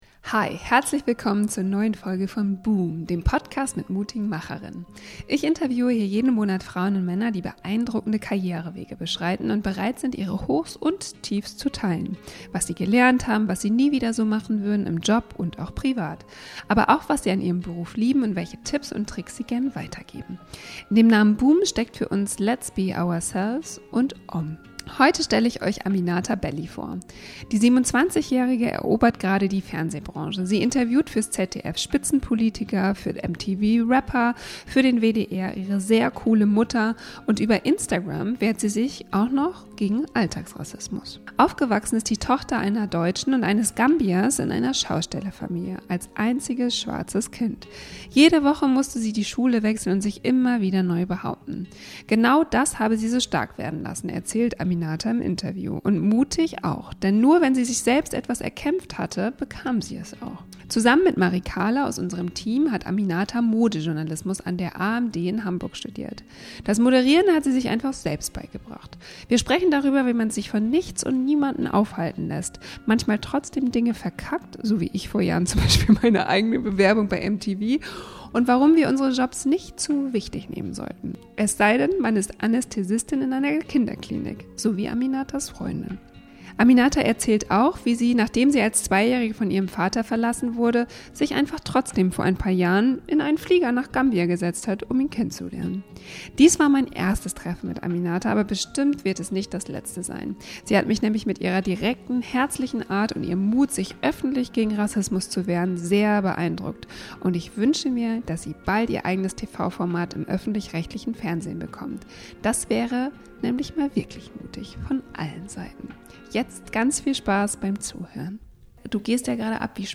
Viel Spaß mit unserem Gespräch!